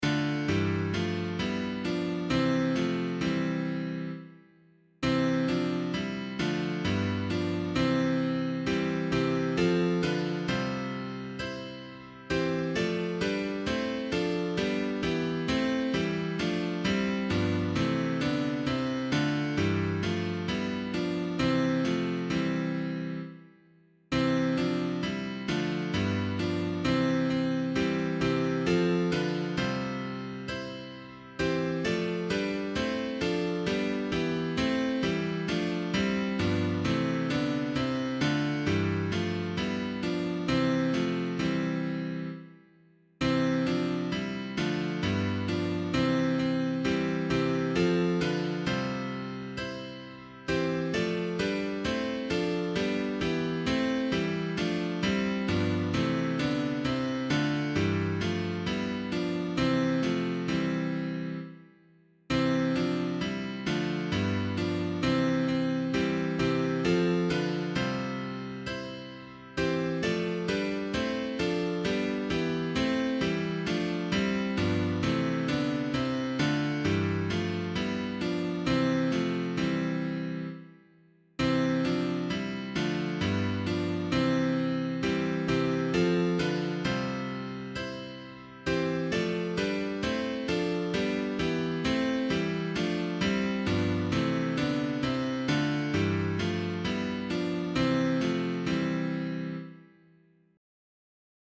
Public domain hymn suitable for Catholic liturgy.
Unto Us a Boy Is Born [Dearmer - PUER NOBIS NASCITUR] - piano.mp3